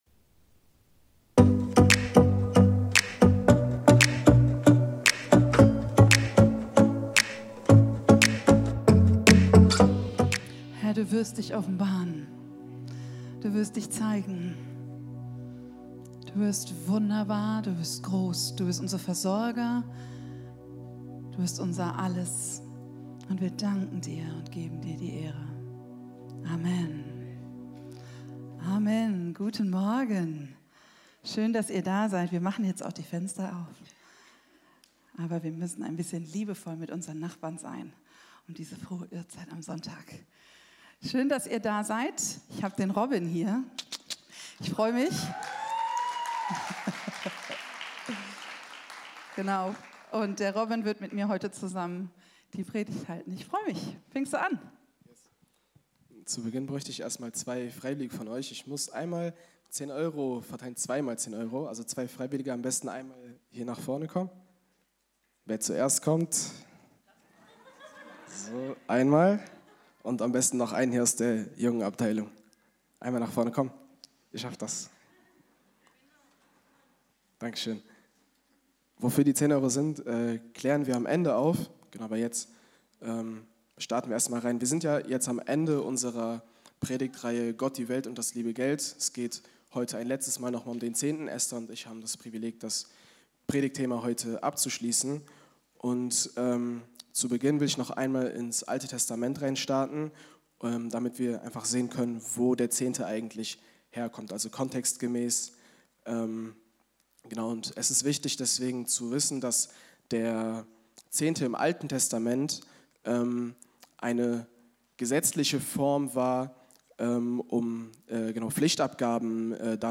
Live-Gottesdienst aus der Life Kirche Langenfeld.
Kategorie: Sonntaggottesdienst Predigtserie: Gott, die Welt und das liebe Geld